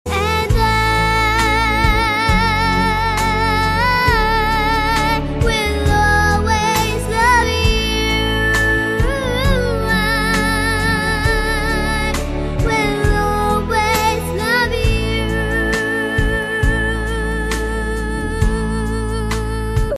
M4R铃声, MP3铃声, 欧美歌曲 47 首发日期：2018-05-14 22:33 星期一